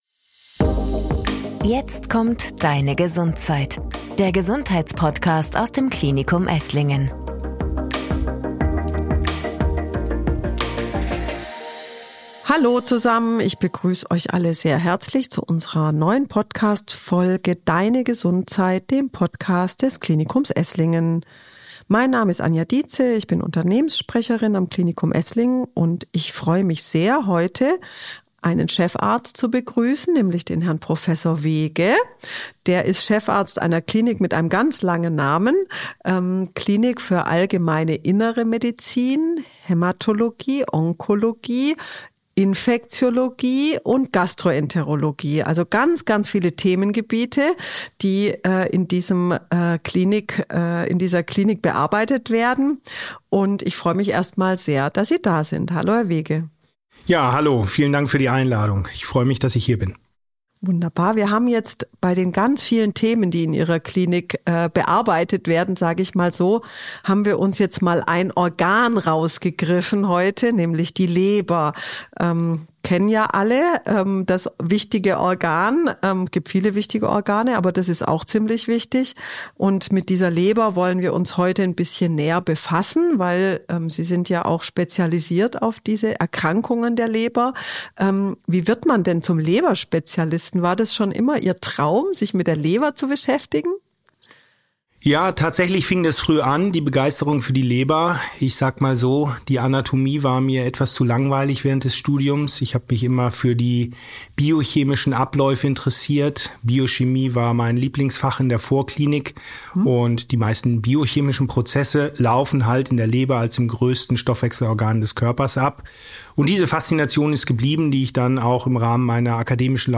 Sie erhalten Informationen über die häufigsten Lebererkrankungen, wie die Heilungschancen stehen und was große Hoffnung macht. Außerdem werden Alkohol und Medikamente zum Thema und unser Leberexperte klärt auf: Wie wirken sich diese wirklich auf die Leber aus?